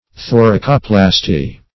Search Result for " thoracoplasty" : The Collaborative International Dictionary of English v.0.48: Thoracoplasty \Tho`ra*co*plas"ty\, n. [Thorax + plasty.]
thoracoplasty.mp3